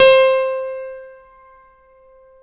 SG1 PNO  C 4.wav